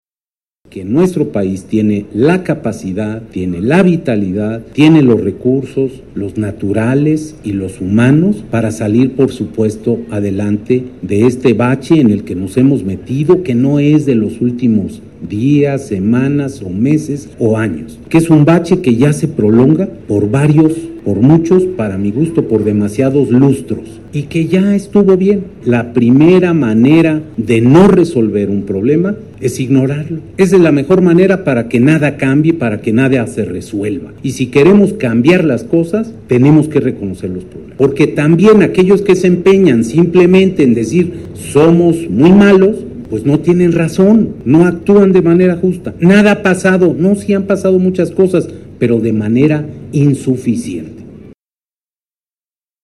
Al inaugurar la Feria de Cómputo UNAM 2009, sostuvo que uno de los elementos que generan los cambios para superar hoy esos retos es la educación, y en particular la superior, así como la investigación, el conocimiento y la cultura en general.